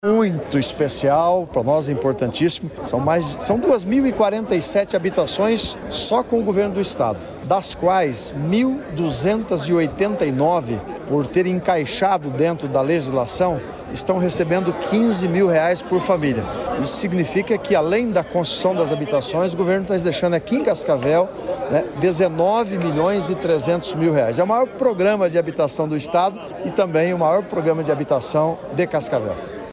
Sonora do prefeito de Cascavel, Leonaldo Paranhos, sobre a entrega de moradias para 85 famílias da cidade